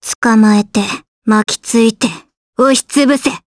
Gremory-Vox_Skill3_jp.wav